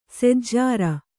♪ sejjāra